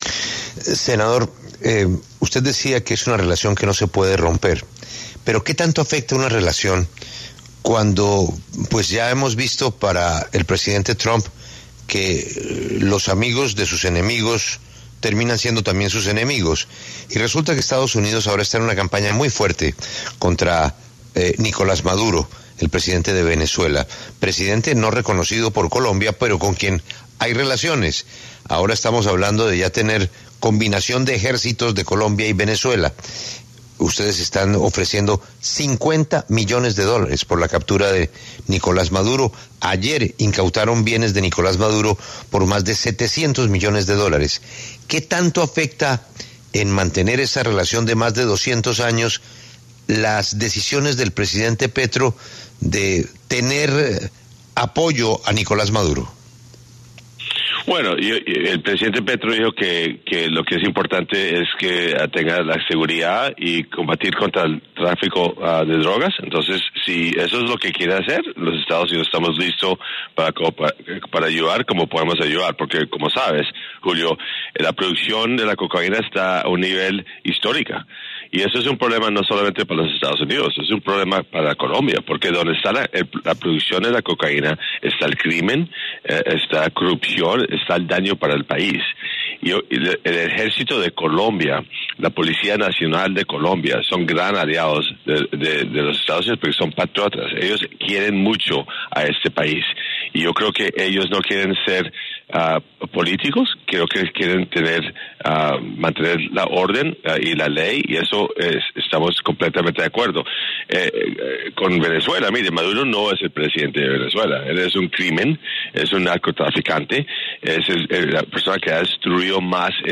En diálogo con La W, el senador estadounidense Bernie Moreno hizo duras críticas al Gobierno del presidente de Venezuela, Nicolás Maduro.